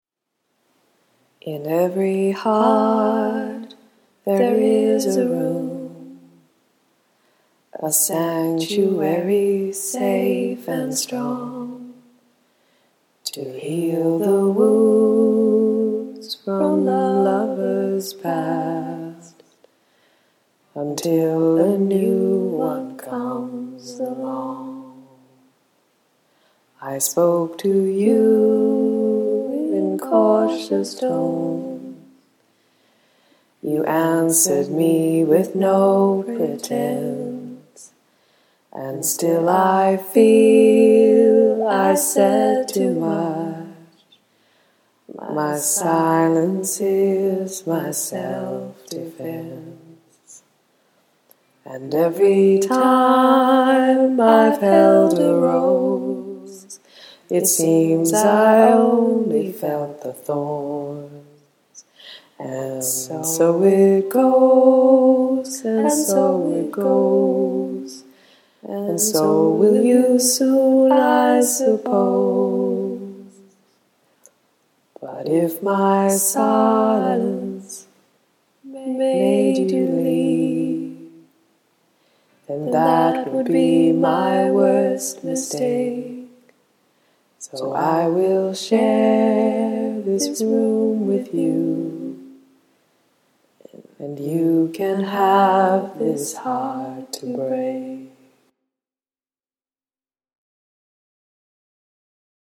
Sometimes you don’t much care that you sing out of tune.